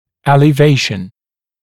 [ˌelɪ’veɪʃn] [ˌэли’вэйшн] поднятие, высокое расположение, элевация, повышение